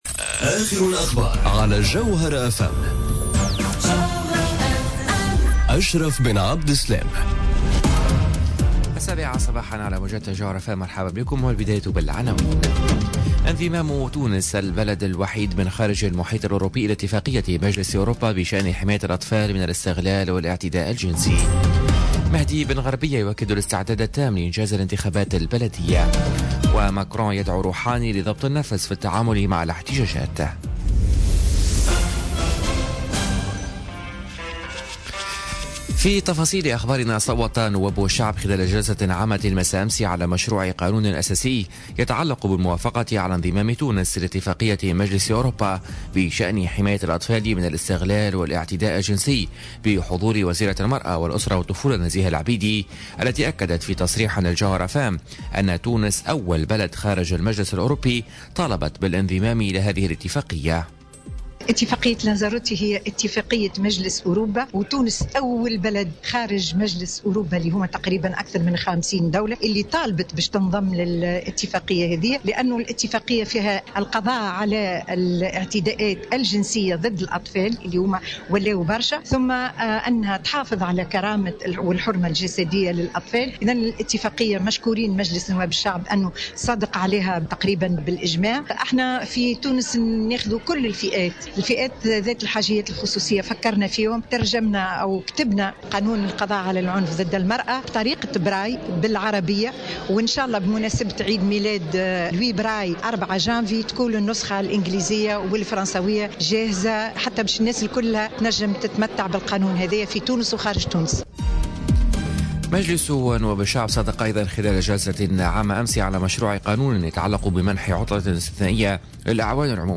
نشرة أخبار السابعة صباحا ليوم الإربعاء 03 جانفي 2018